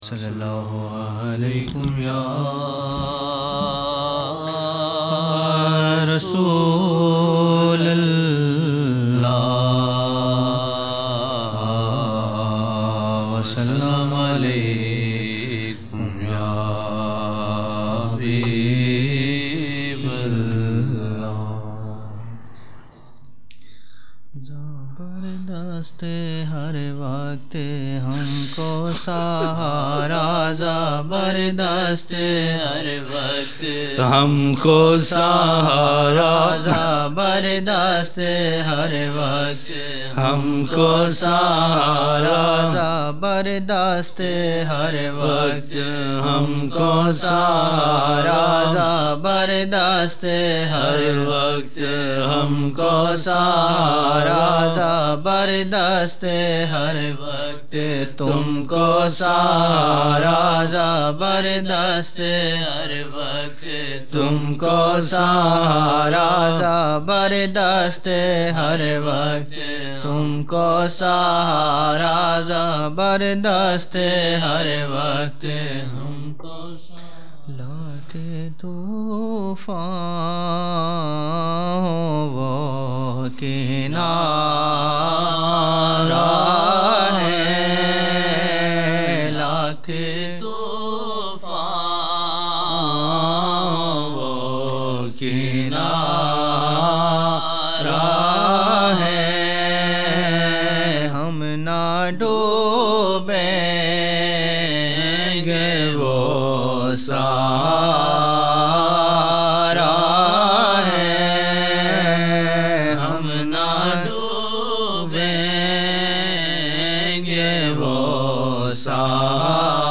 23 November 1999 - Zohar mehfil (15 Shaban 1420)